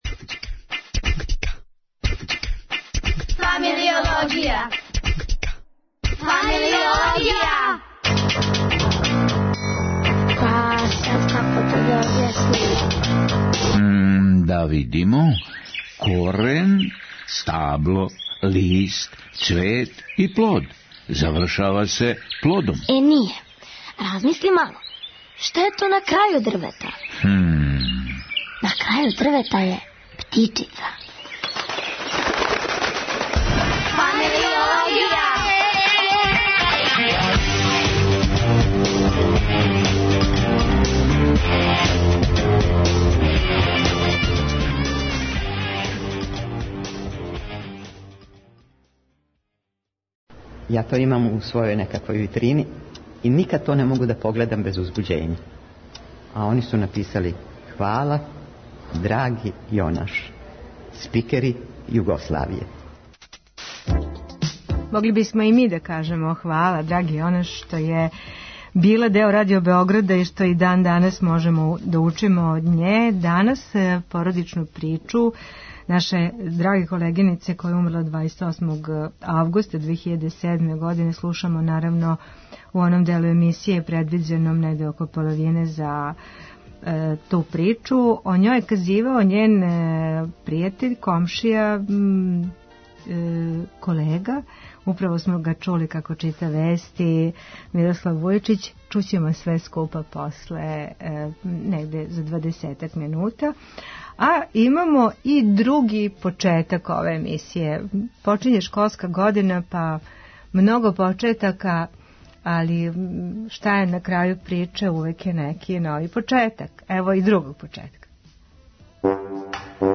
Чућемо и Бранка Ћопића и његово сећање на први дан у школи, али и одломак из књиге "Тата ти си луд" Вилијема Саројана који је рођен на данашњи дан 1908. године.